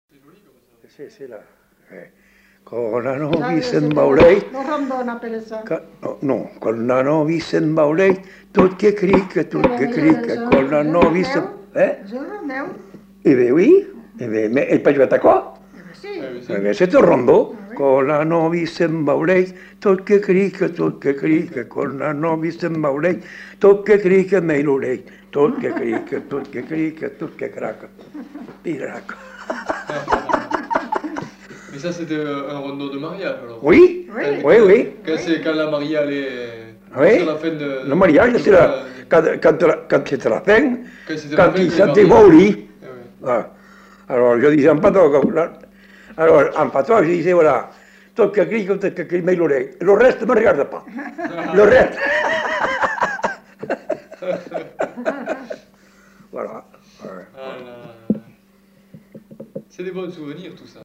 Aire culturelle : Bazadais
Genre : chant
Effectif : 1
Type de voix : voix d'homme
Production du son : chanté
Danse : rondeau